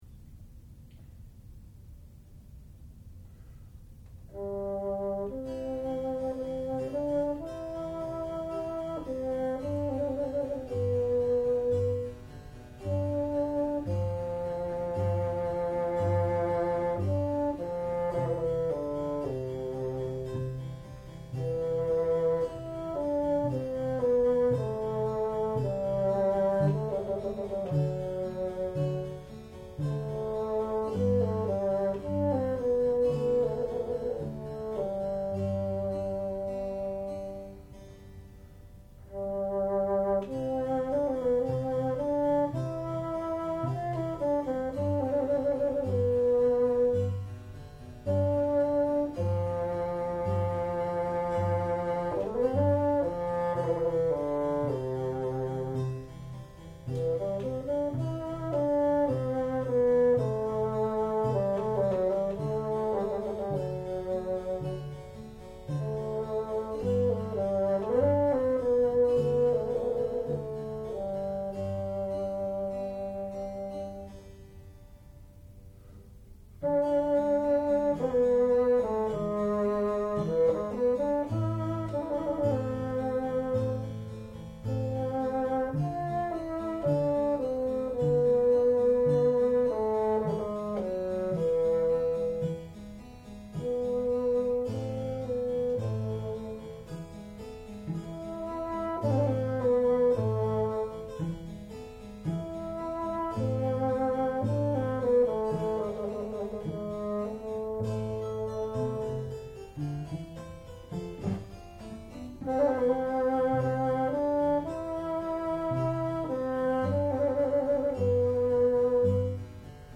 sound recording-musical
classical music
Junior Recital